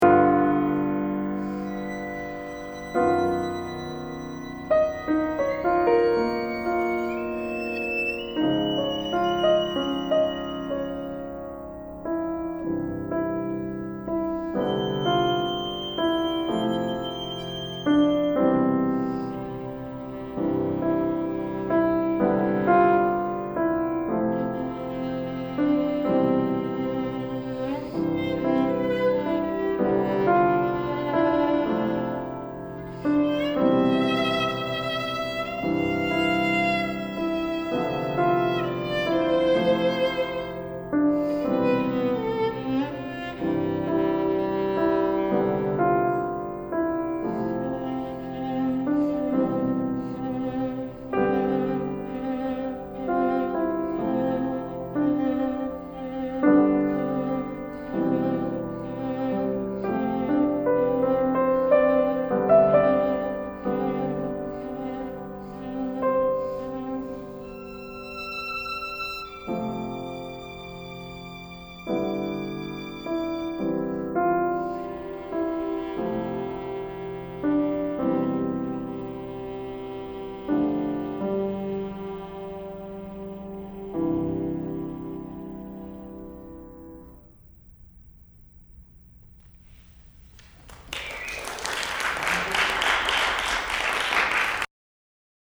demo recording [ending only]